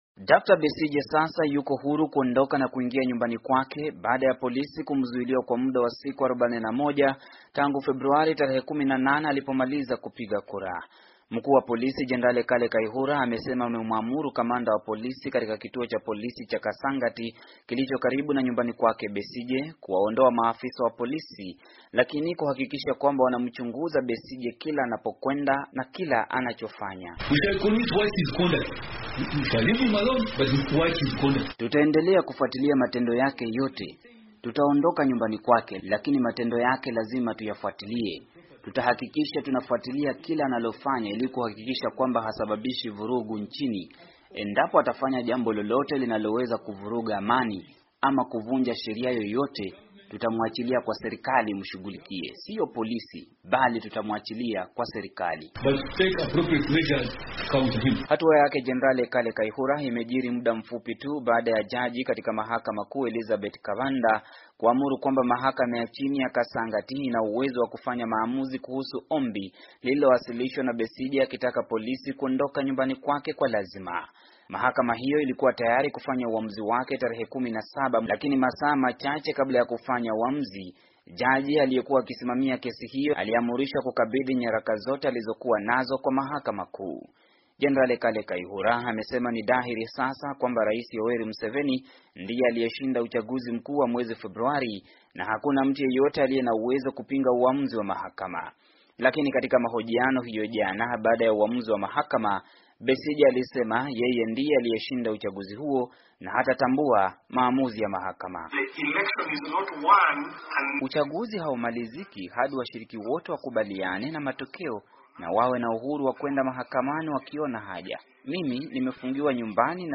Ripoti ya mwandishi wetu